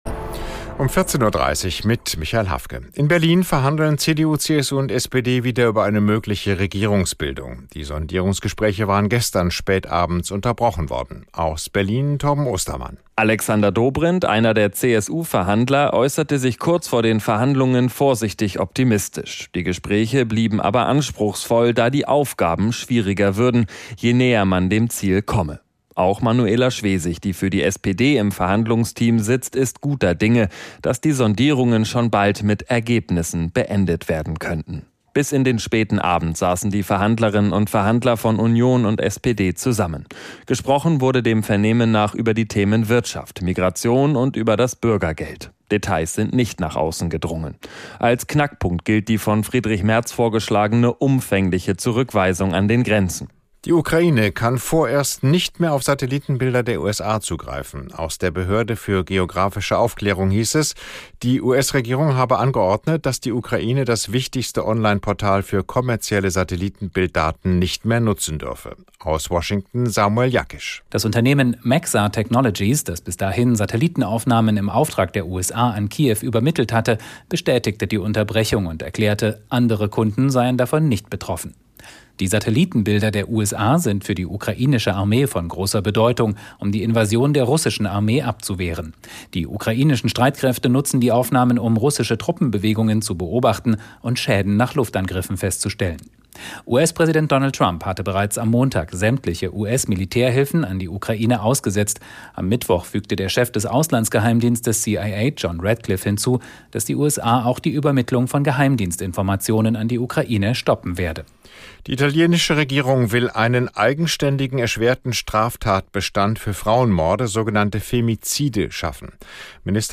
Nachrichten - 08.03.2025